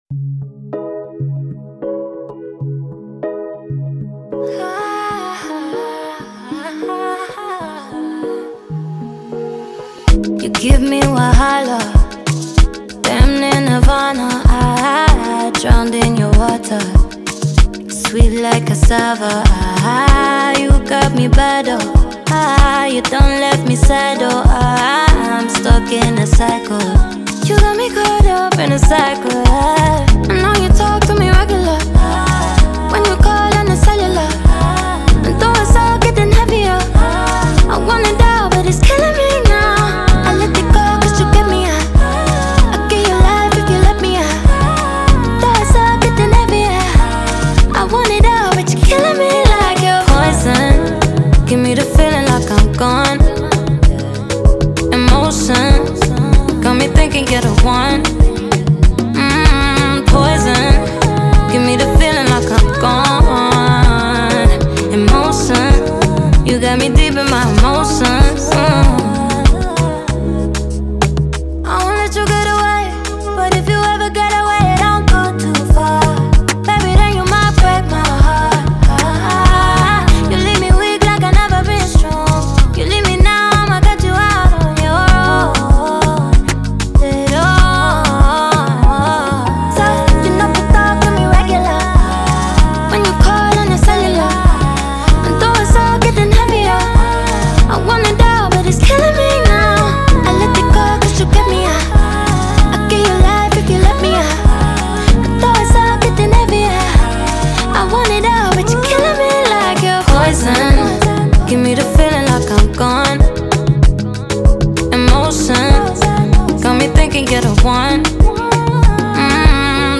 • Жанр: R&B, Soul